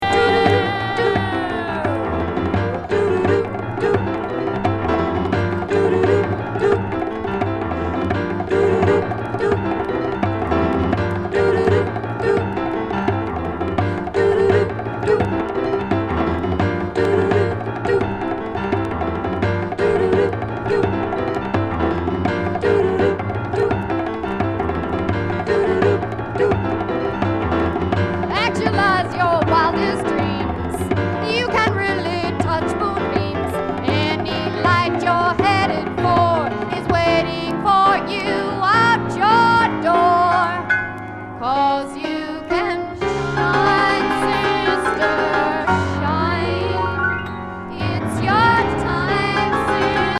*A1、B1に盤歪み起因のノイズあります。